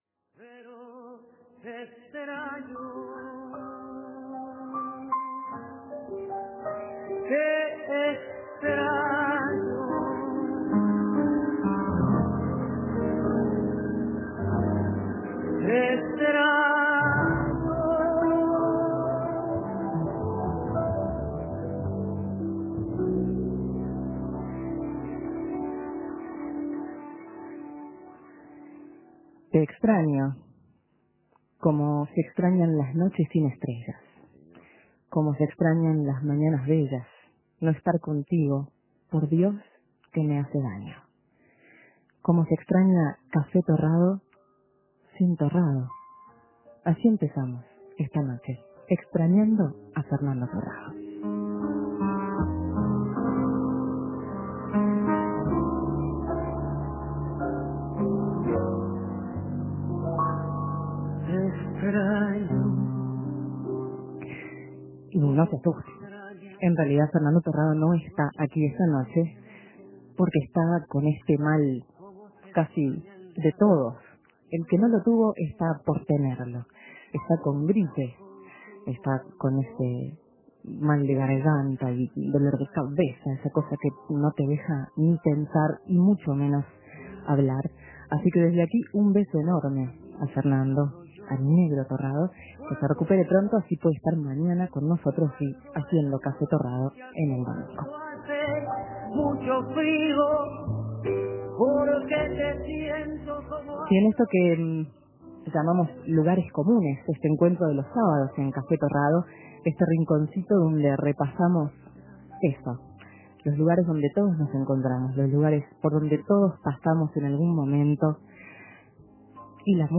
Boleros en Café Torrado.